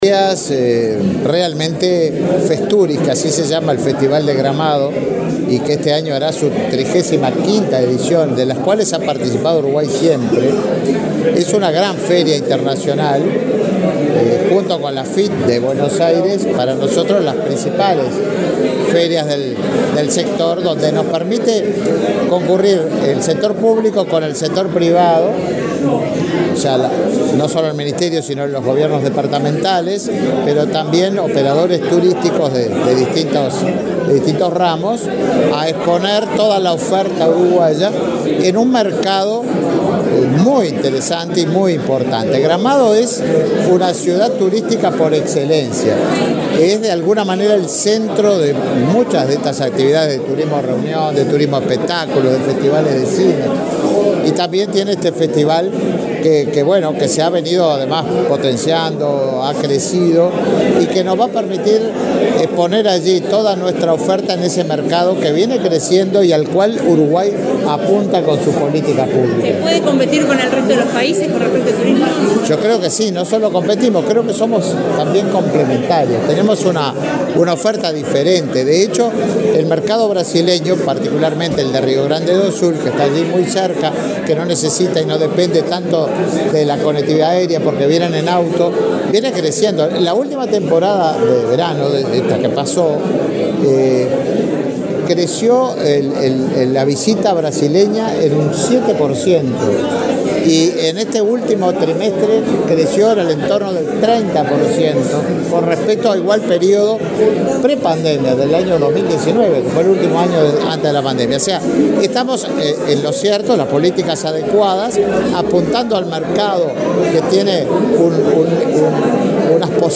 Declaraciones a la prensa del ministro de Turismo, Tabaré Viera
Declaraciones a la prensa del ministro de Turismo, Tabaré Viera 31/08/2023 Compartir Facebook X Copiar enlace WhatsApp LinkedIn El ministro de Turismo, Tabaré Viera, participó del lanzamiento de Festuris, una feria internacional de turismo de Gramado, en Brasil. Luego, dialogó con la prensa.